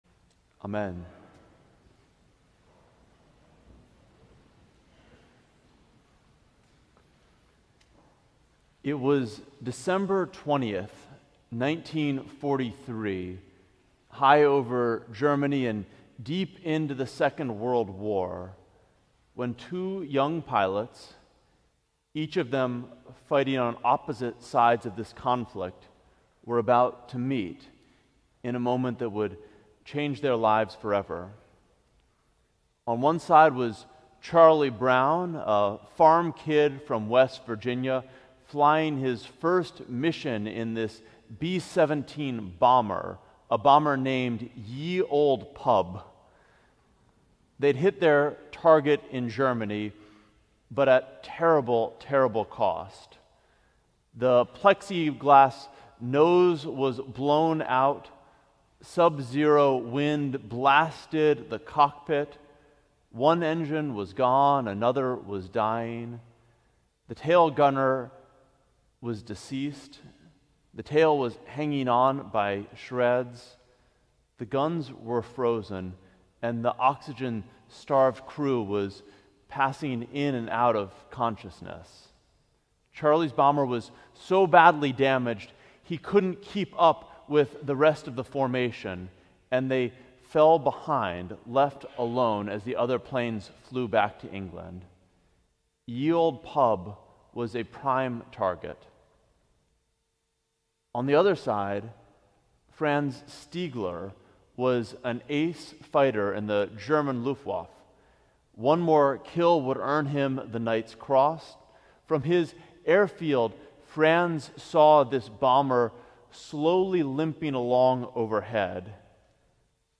Sermon: The Wolf and the Lamb at 20,000 Feet - St. John's Cathedral